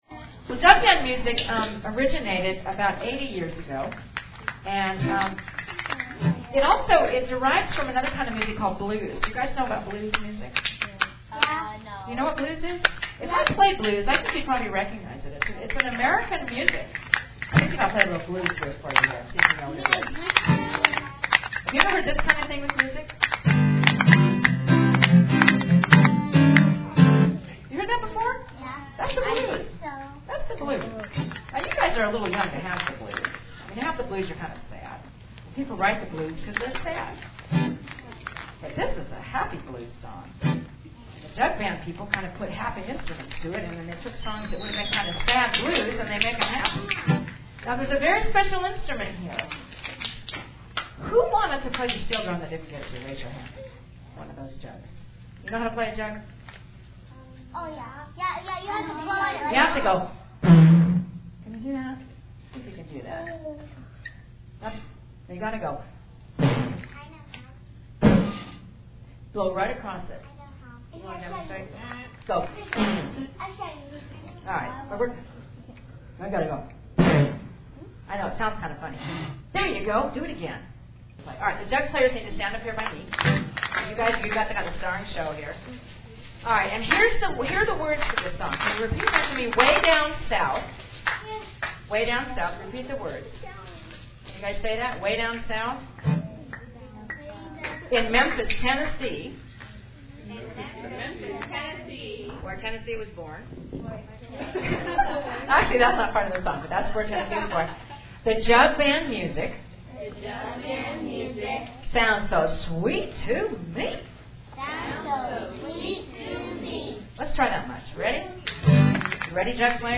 Down Home Music for Modern Kids
The Jumpin' Up!tm Jug Band
Traditional